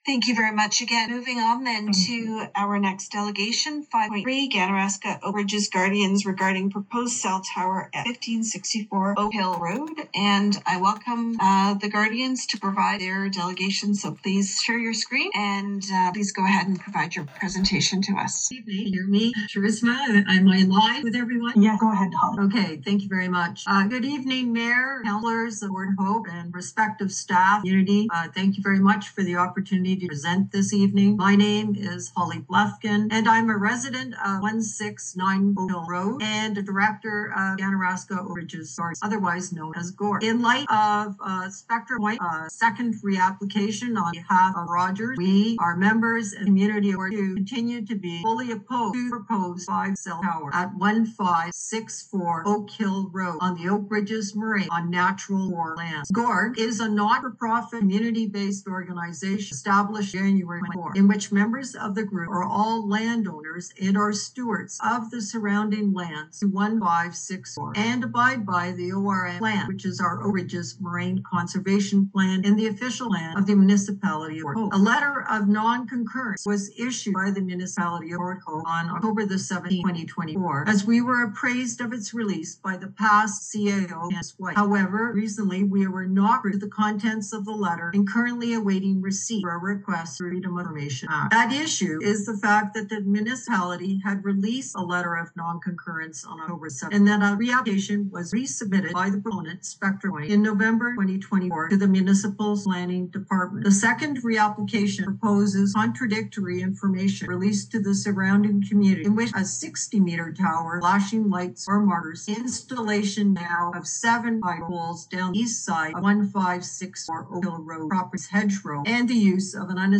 Port Hope council heard a presentation from the Ganarasak Oak Ridges Guardians in opposition to a cell tower at 1564 Oak Hill Road at a meeting on July 15.
Here is the presentation and the council’s reaction: